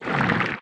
Sfx_creature_spikeytrap_reel_03.ogg